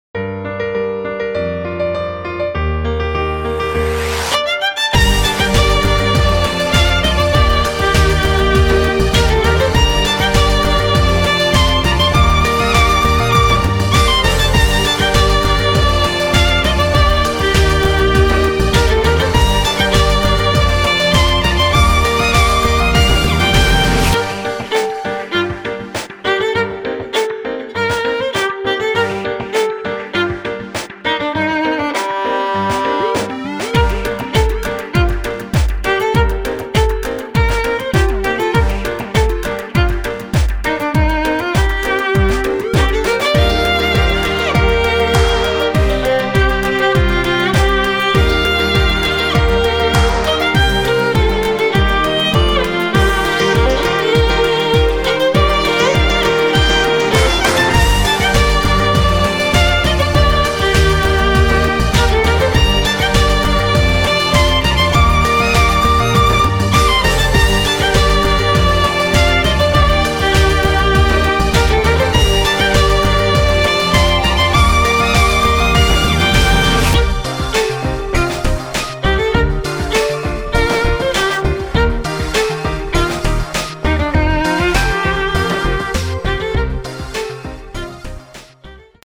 Les musiques d'ambiance